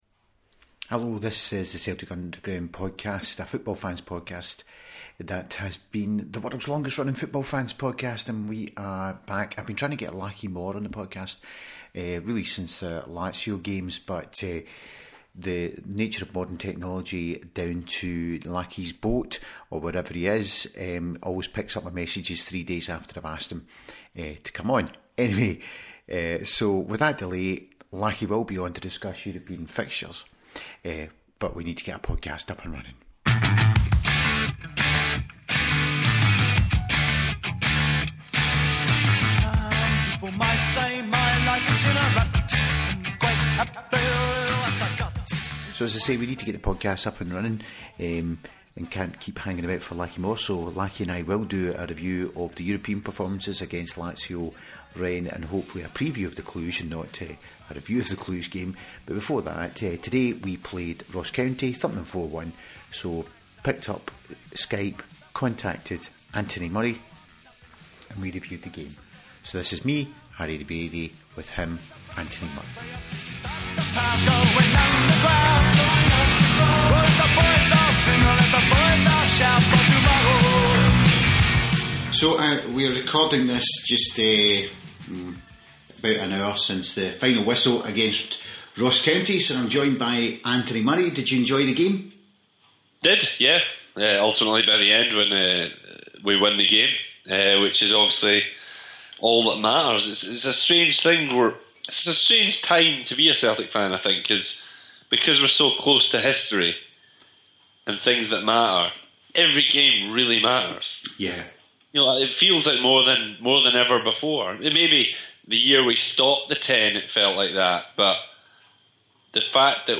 Within an hour of the final whistle I was on Skype